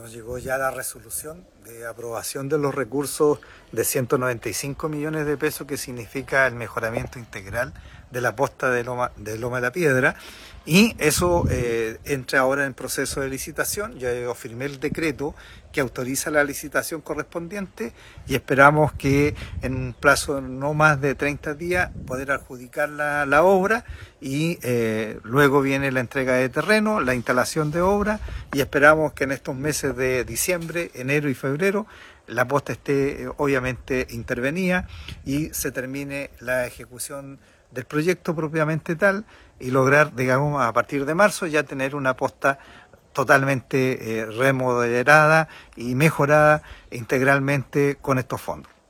La noticia fue confirmada por el alcalde de San Juan de la Costa, Bernardo Candia, que comentó que proyecto tiene como objetivo mejorar la calidad de la atención y la satisfacción de los usuarios que acuden a este establecimiento de salud.